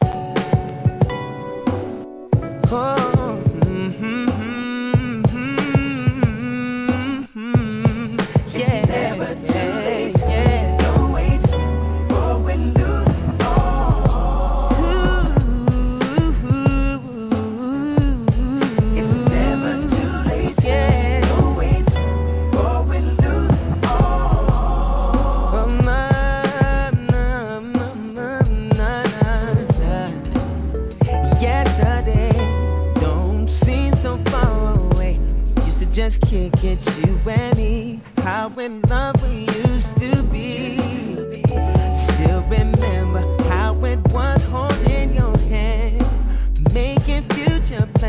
HIP HOP/R&B
素晴らしいソウルフルなヴォーカルで聴かせます!